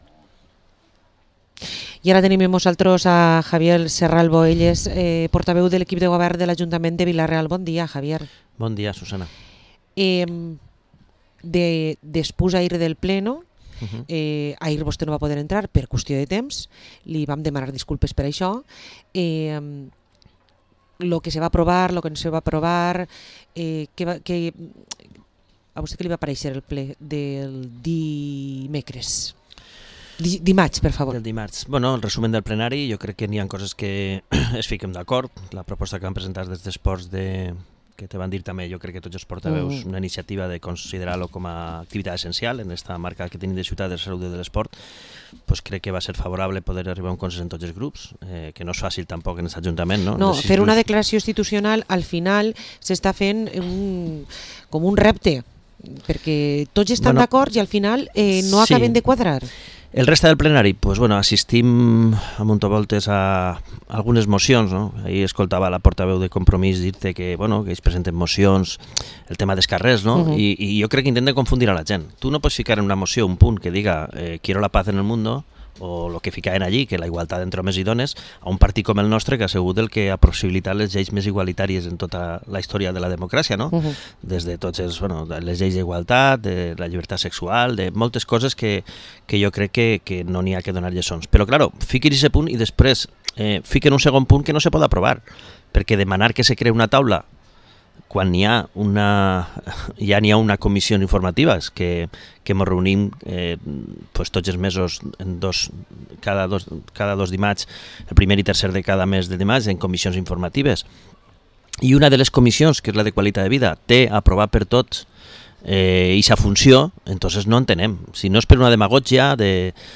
Entrevista a Javier Serralvo, portavoz del equipo de gobierno en el Ayuntamiento de Vila-real